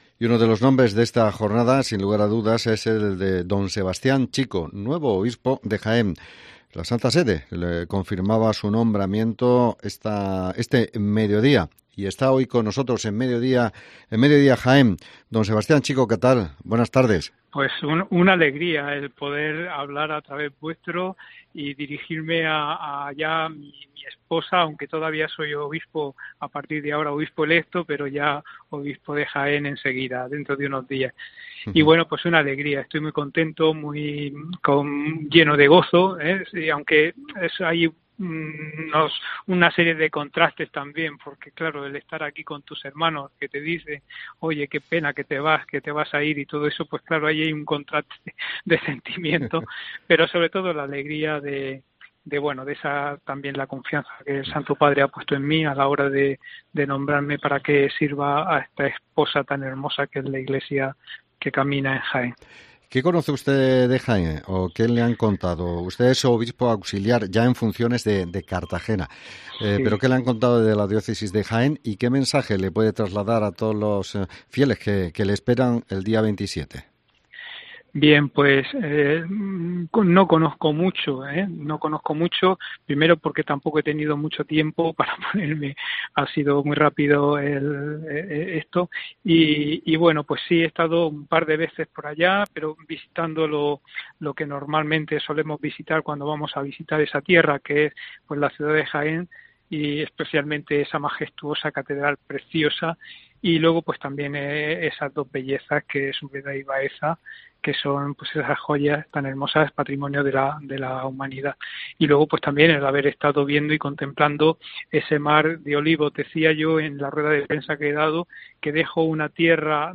Hablamos con el nuevo Obispo de Jaén, Don Sebastián Chico